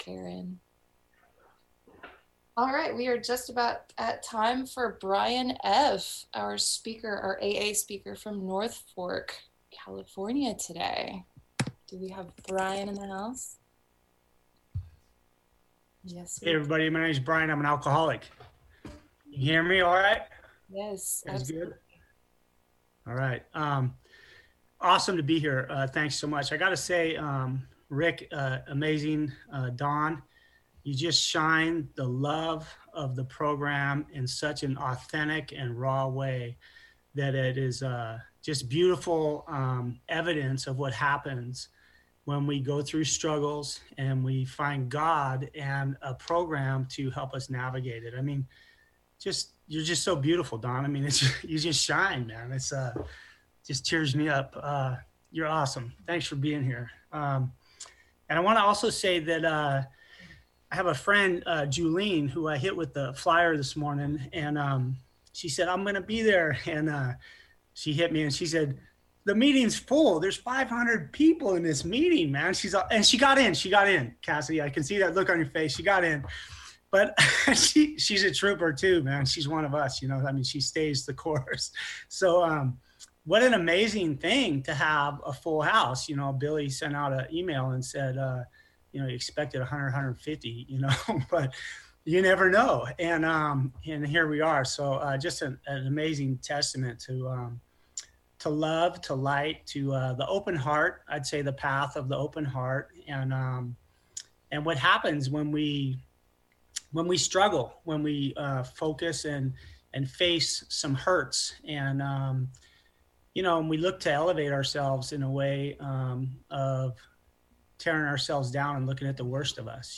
AWB Sunday Special Speaker Meeting